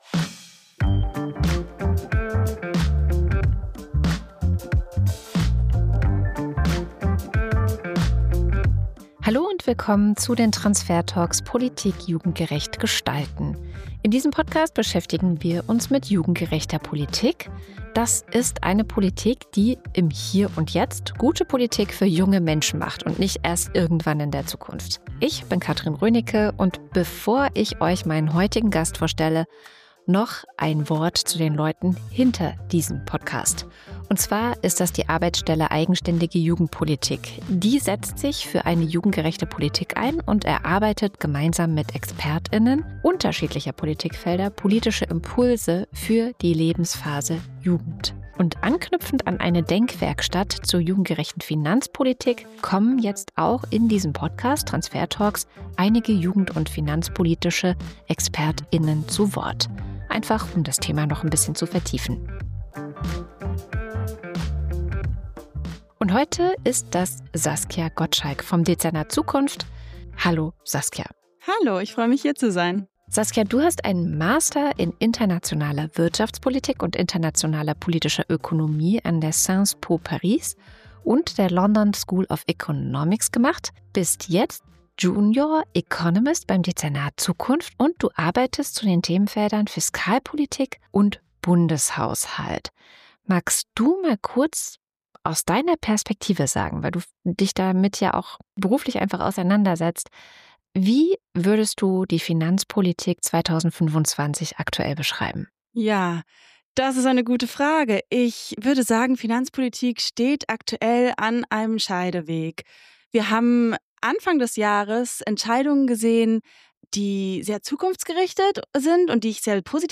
Das Gespräch zeigt, warum es mehr braucht als Sparpolitik und kurzfristige Haushaltslogik: Eine jugendgerechte Finanzpolitik denkt langfristig, fördert Innovationen und investiert in kommende Generationen, statt Vergangenes zu subventionieren. Wir sprechen darüber, warum Schulden nicht nur ein Risiko, sondern auch eine Chance für einen funktionierenden Sozialstaat sein können und wieso es Zeit ist für ein neues Verständnis von guter Finanzpolitik. Moderation